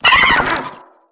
Sfx_creature_penguin_flinch_land_02.ogg